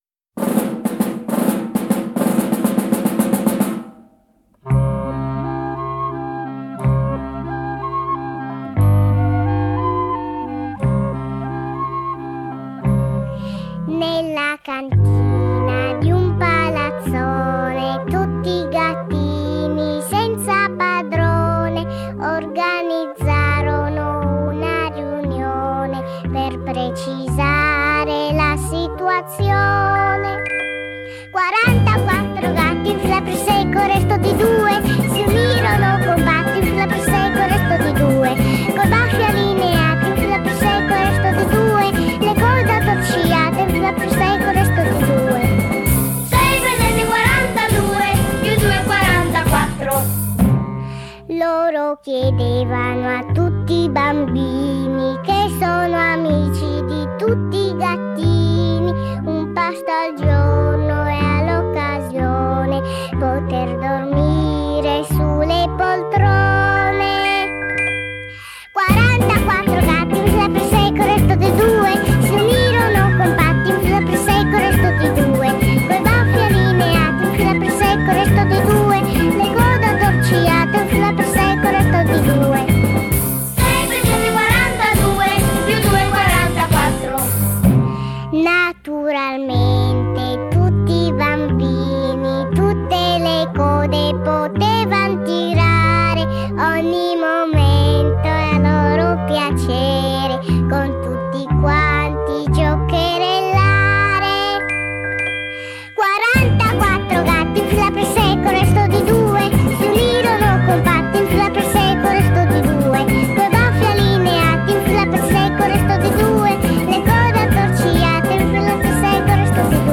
Genere: Pop.